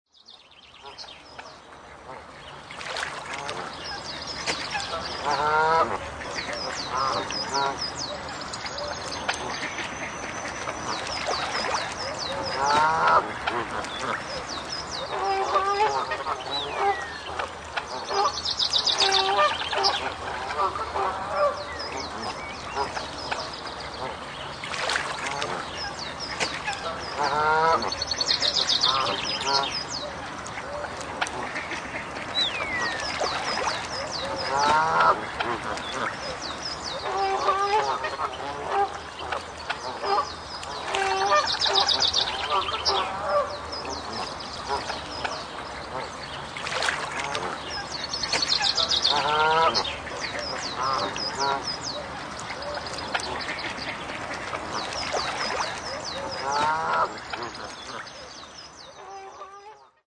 Lakeside Dawn Chorus
Category: Animals/Nature   Right: Personal